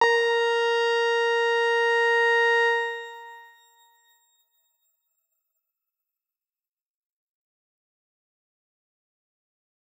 X_Grain-A#4-pp.wav